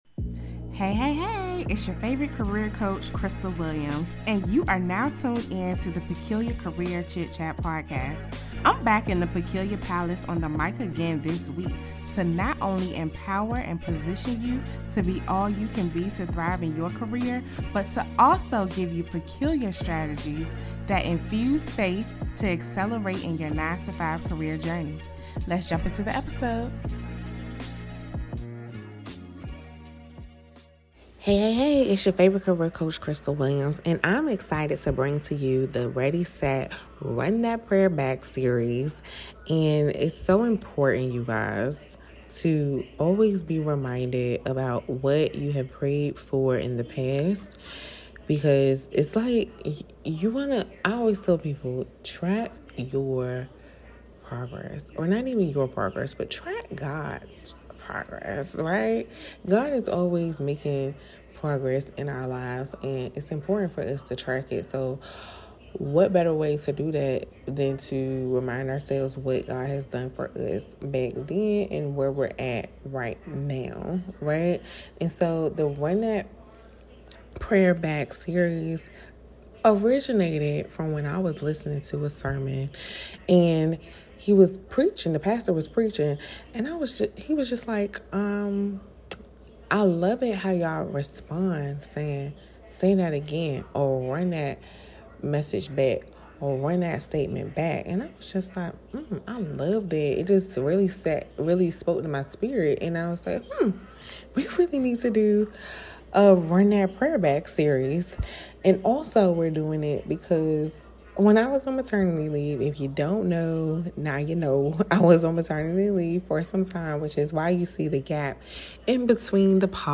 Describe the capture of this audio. (Limited Series) This is the LIVE call we did on Christmas Day and wheeww so much good info and declarations to say over yourself!